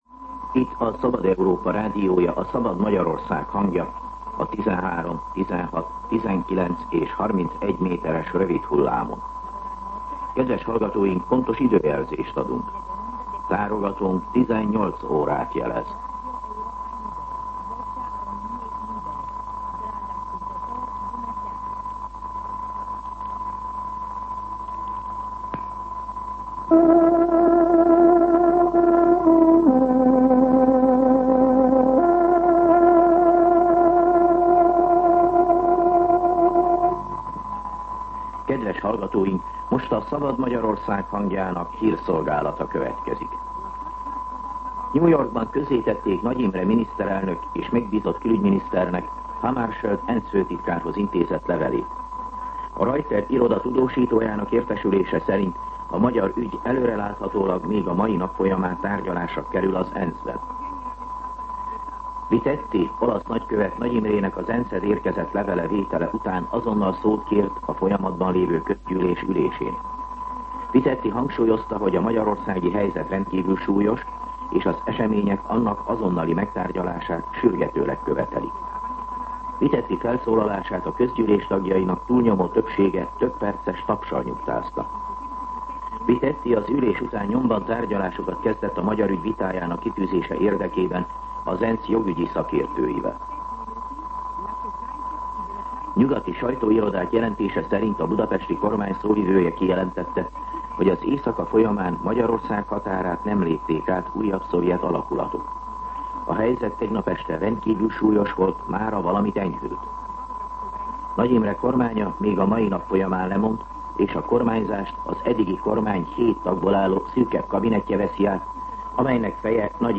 Hírszolgálat